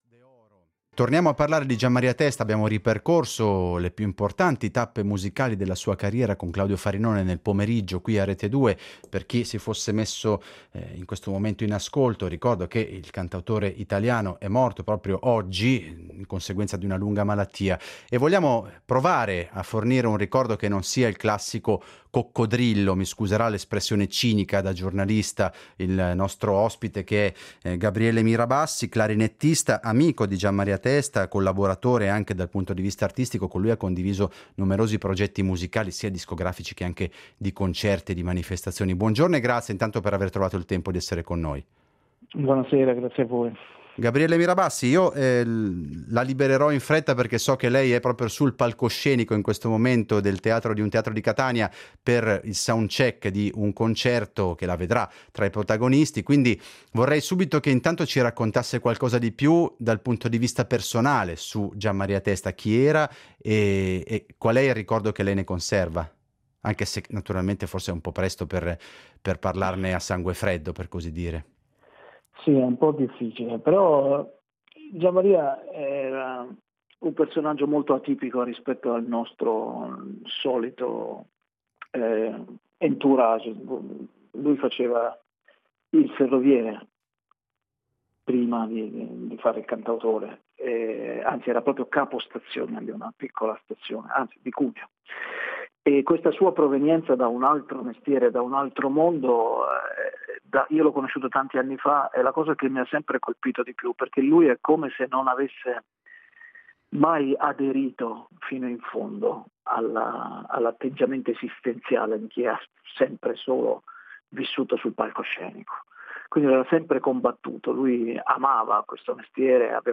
È scomparso all’età di 57 anni, dopo una coraggiosa lotta contro la malattia che lo colpì circa un anno fa, il cantautore italiano Gianmaria Testa, testimonianza di Gabriele Mirabassi